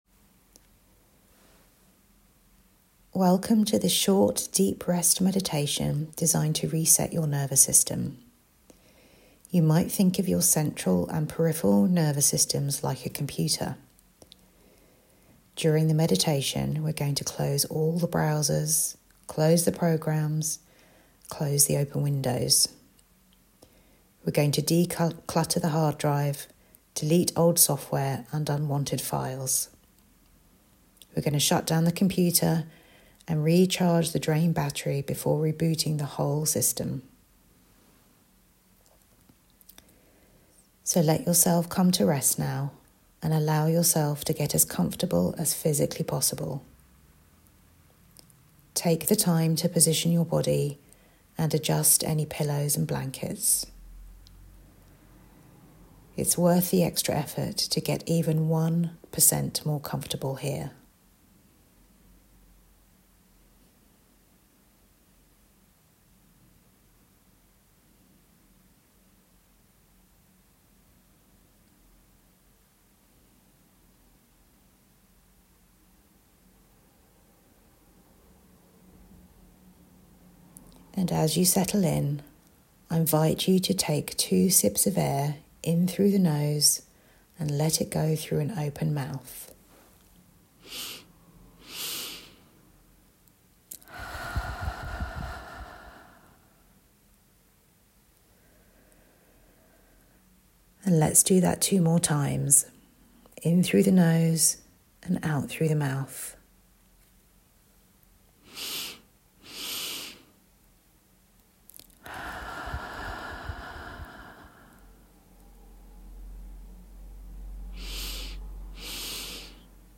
Deep Rest Meditation – nervous system reset